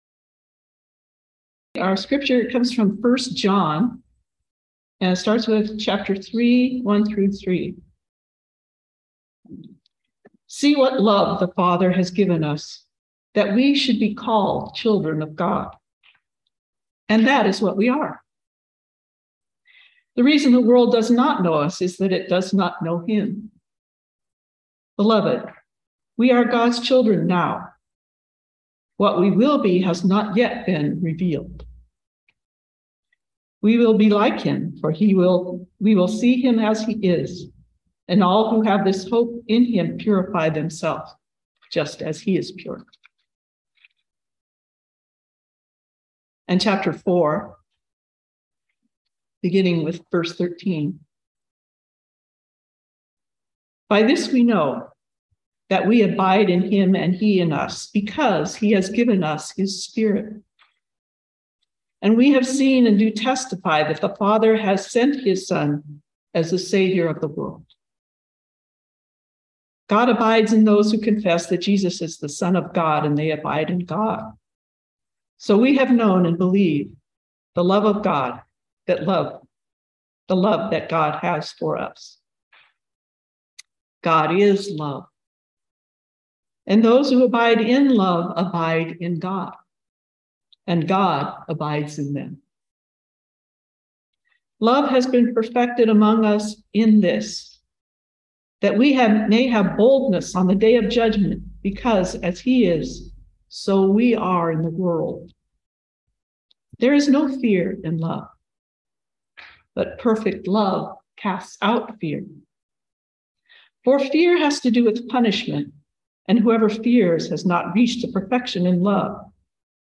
Listen to the most recent message from Sunday worship at Berkeley Friends Church, “Integrity.”